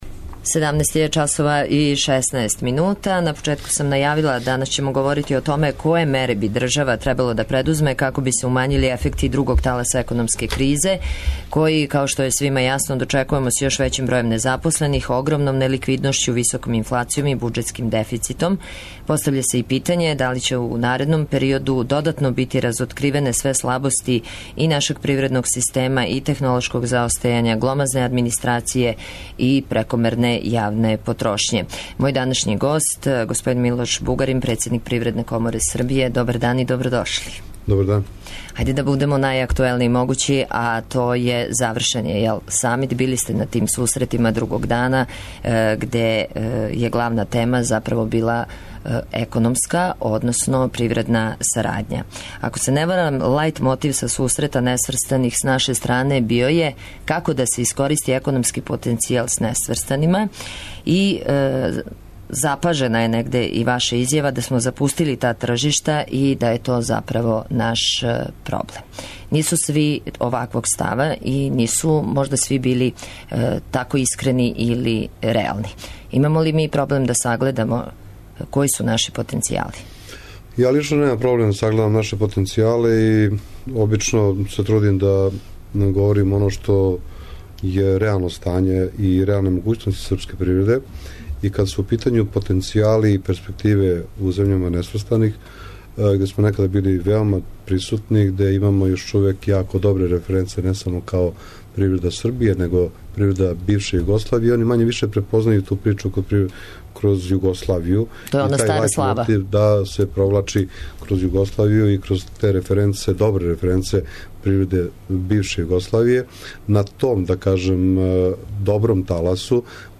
Гост је Милош Бугарин, председник Привредне коморе Србије.